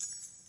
Keys Jingling
描述：Keys being jingled.
标签： jingle key keysjingling multiplekeys jingling keys multiple sound
声道立体声